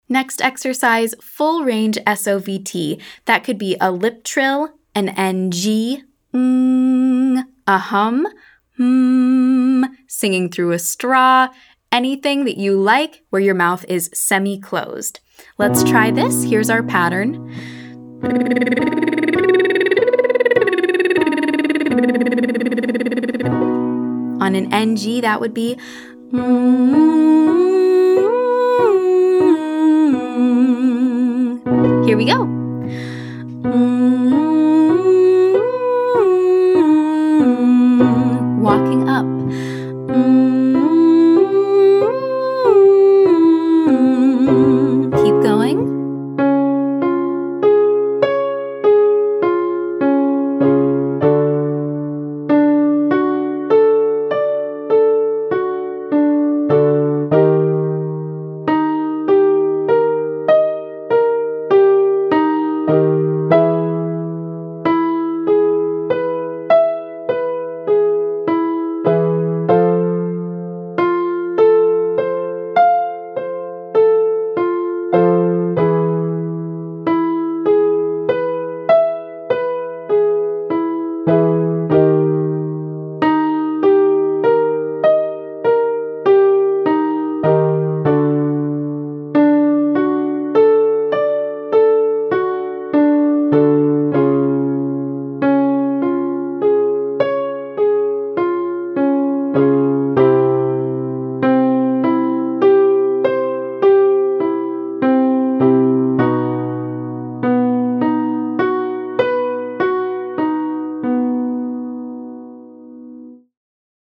Quick warmup